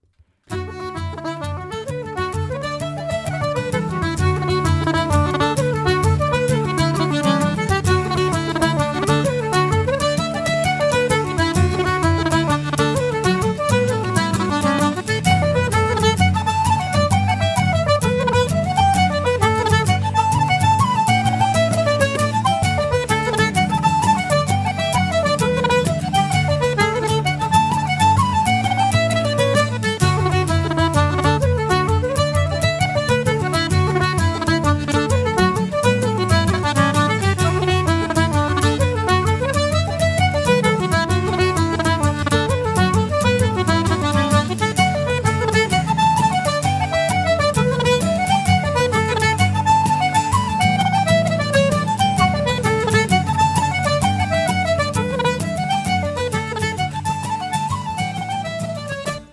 • Plays a variety of traditional Irish instruments
This energetic Irish band has emerged from the world of Gaelic music, adopting a traditional yet contemporary style.
Using a variety of acoustic instruments they create an unrivalled sound of pretty melodies at an upbeat tempo.
Ceili Jig
Ceili Jig.mp3